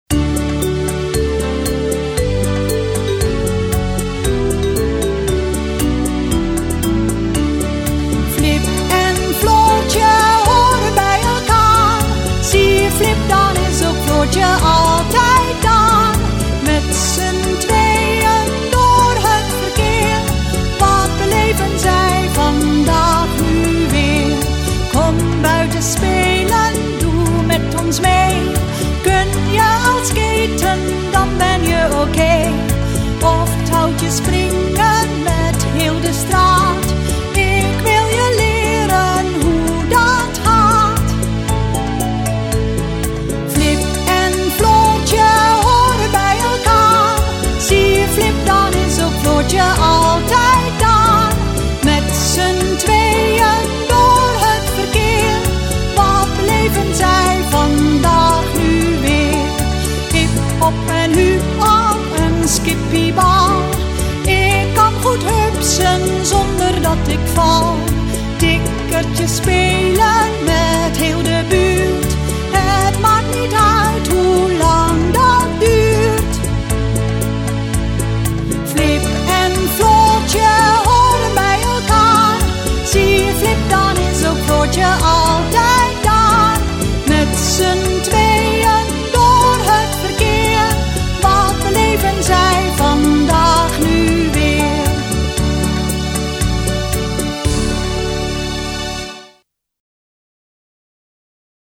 Muziek gezongen